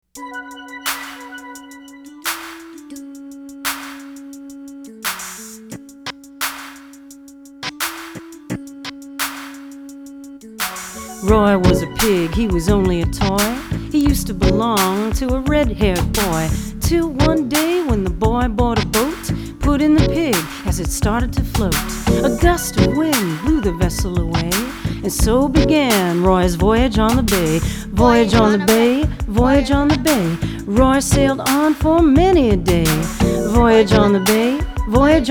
Vocal and